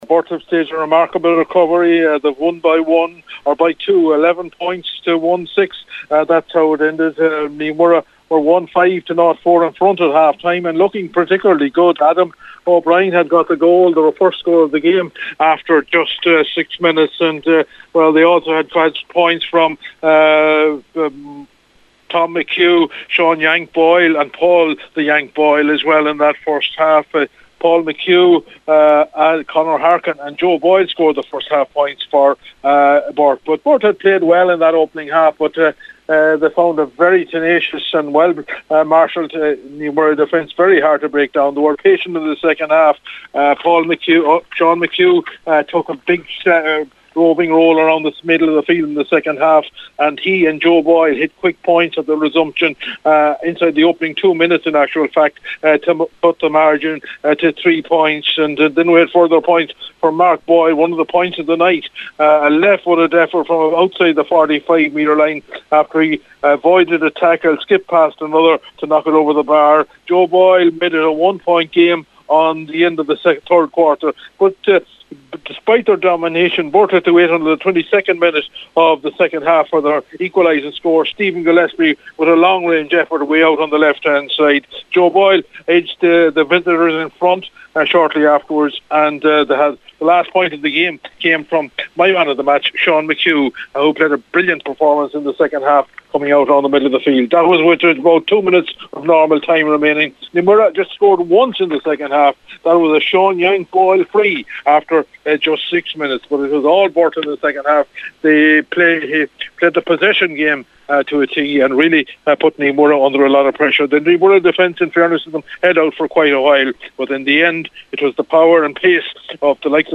reports from The Banks on a good evening for the Burt men…